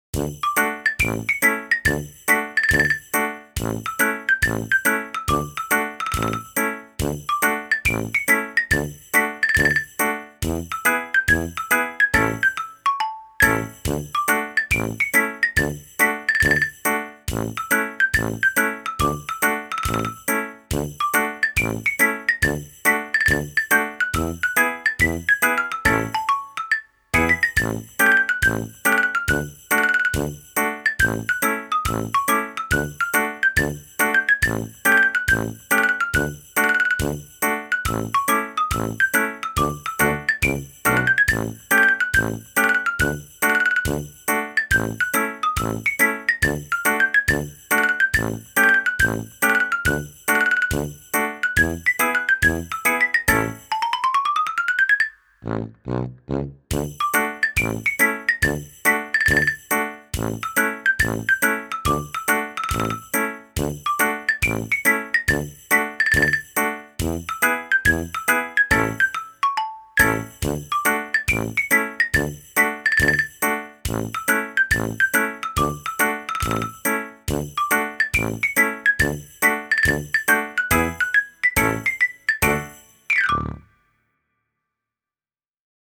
Musik Latar Belakang.mp3